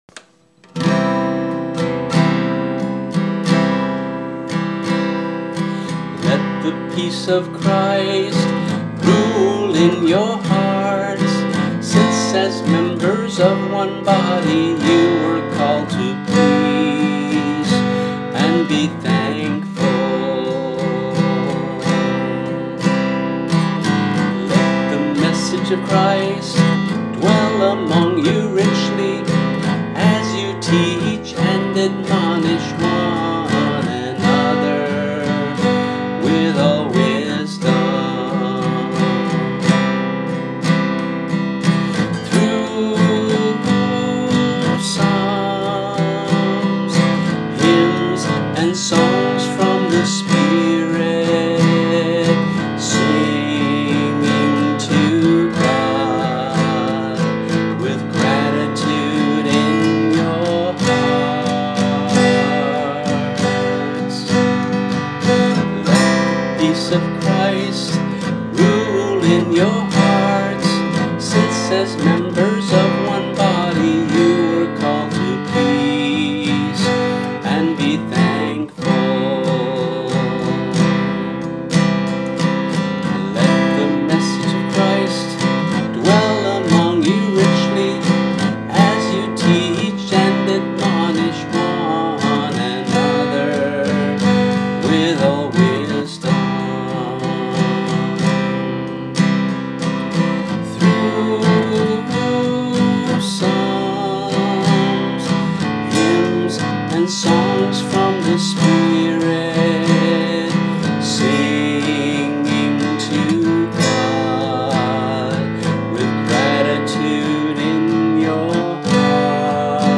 voice and guitar